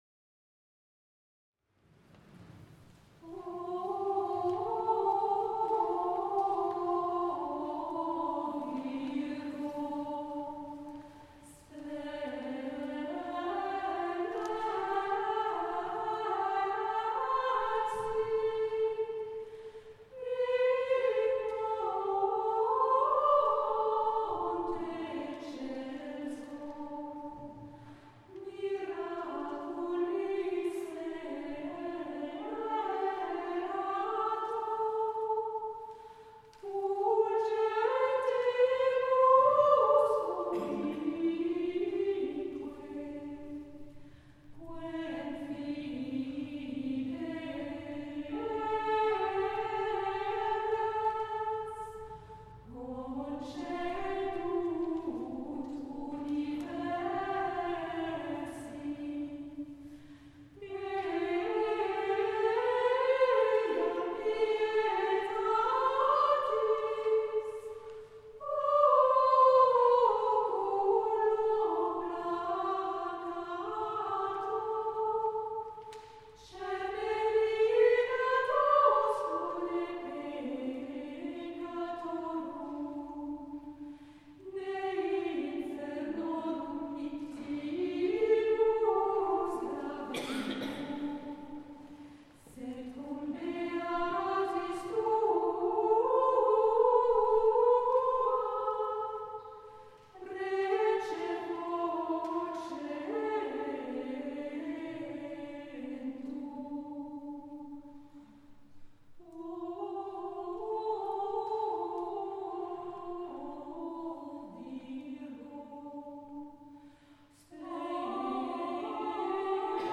Noëls des XIVe au XXIeme siècle